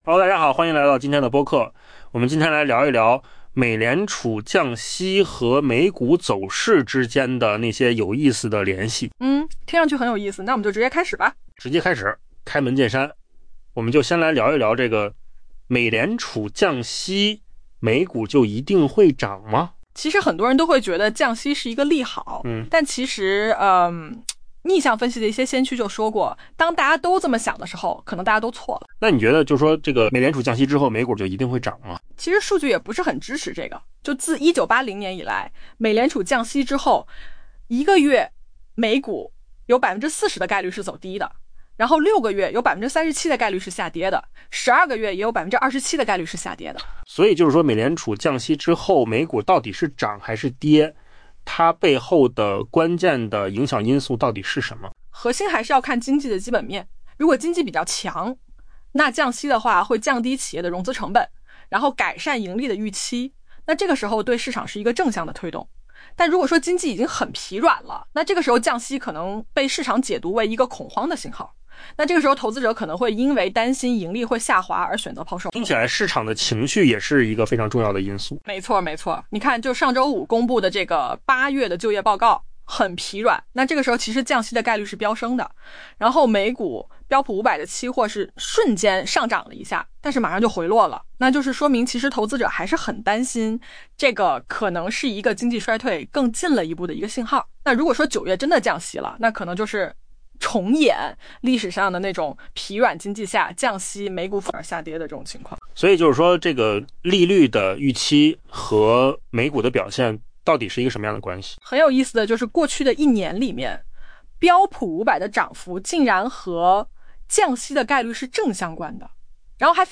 AI 播客：换个方式听新闻 下载 mp3 音频由扣子空间生成 许多投资者认为，美联储降息对美股来说是利好消息。